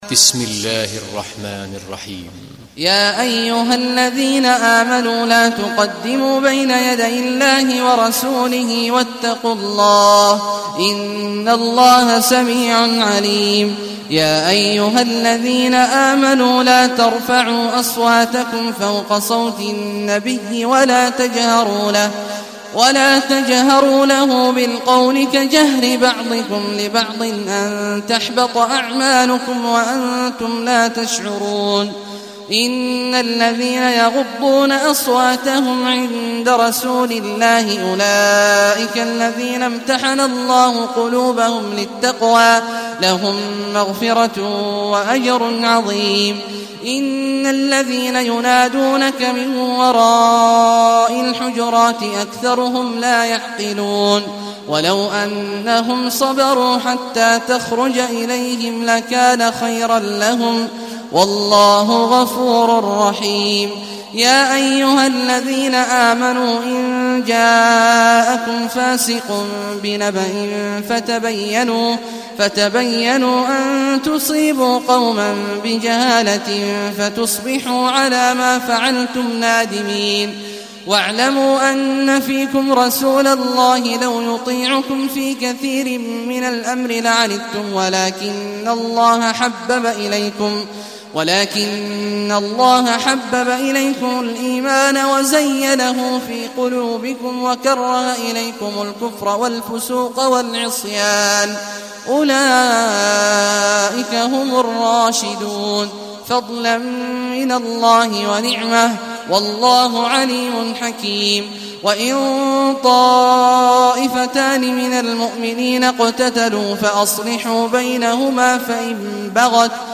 Surah Al Hujurat Download mp3 Abdullah Awad Al Juhani Riwayat Hafs from Asim, Download Quran and listen mp3 full direct links